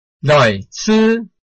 臺灣客語拼音學習網-客語聽讀拼-饒平腔-開尾韻
拼音查詢：【饒平腔】loi ~請點選不同聲調拼音聽聽看!(例字漢字部分屬參考性質)